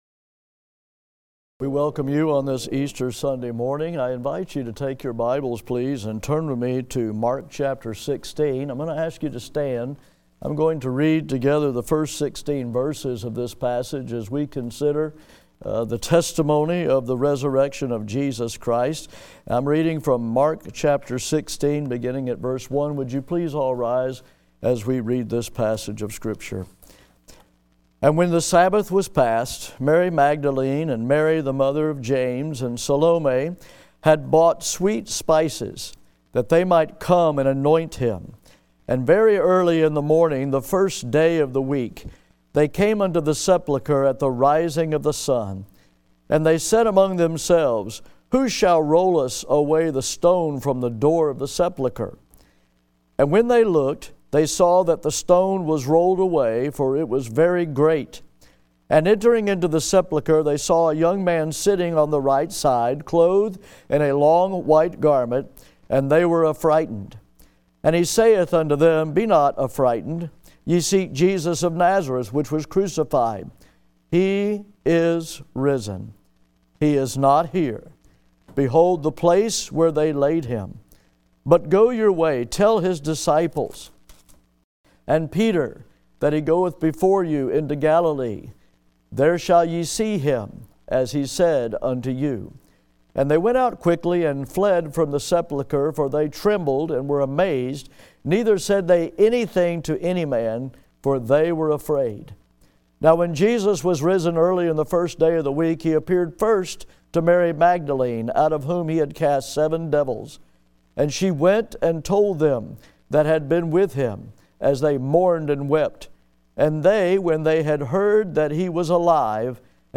GRACE BIBLE CHURCH Audio Sermons
2025 Call To Worship